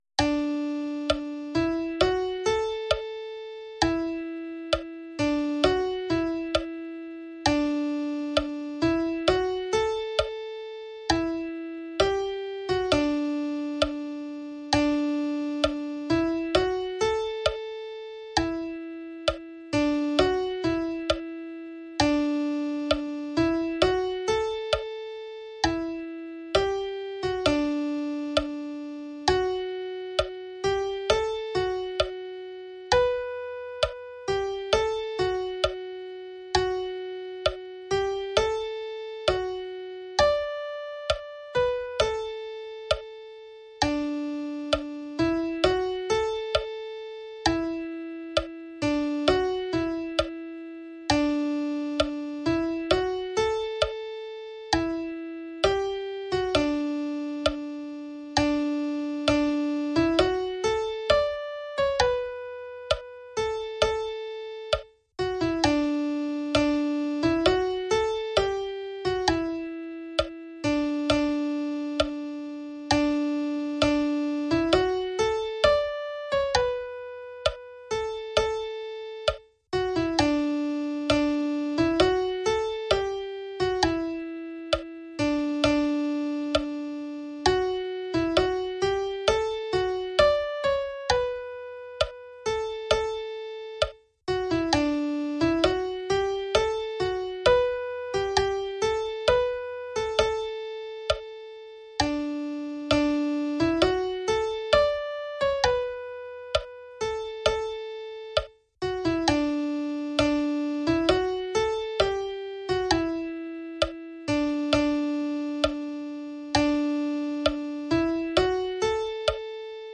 AUDIO PARTITURA MIDI–